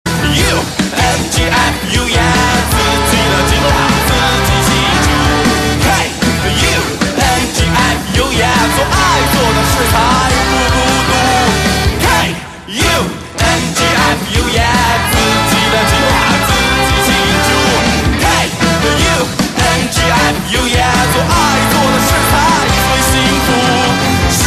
M4R铃声, MP3铃声, 华语歌曲 30 首发日期：2018-05-15 22:14 星期二